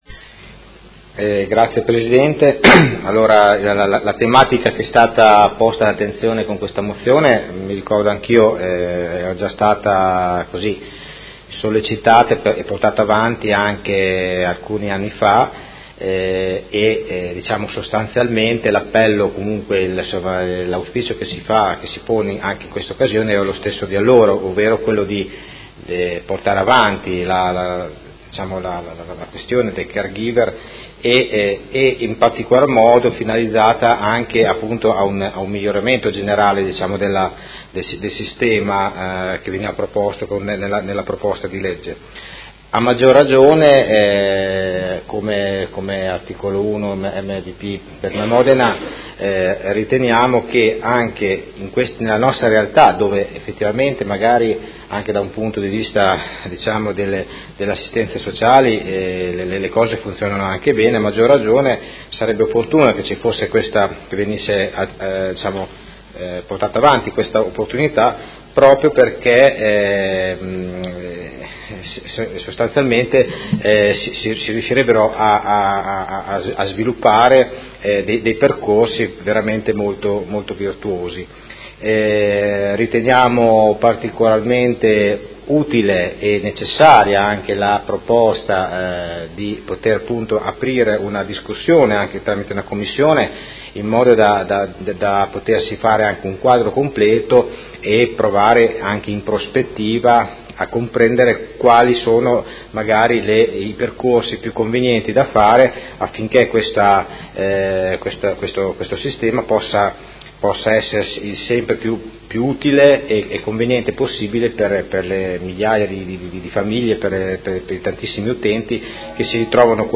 Vincenzo Walter Stella — Sito Audio Consiglio Comunale